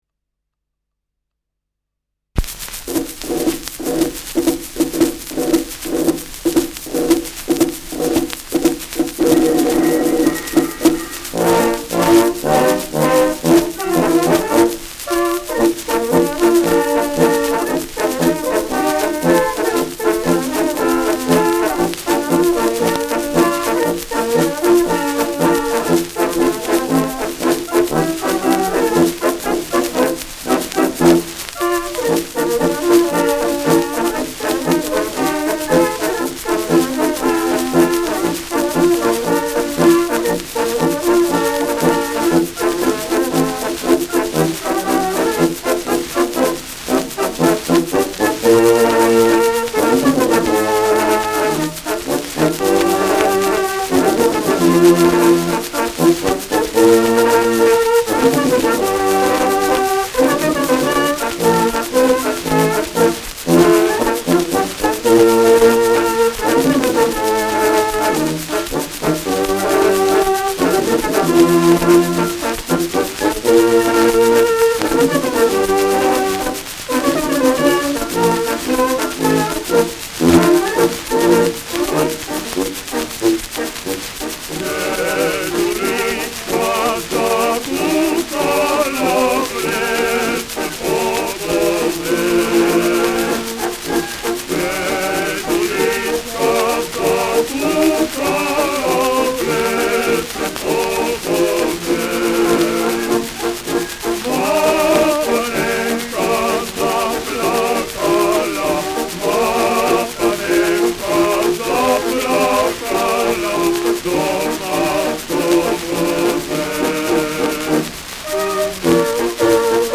Schellackplatte
präsentes Rauschen : Knistern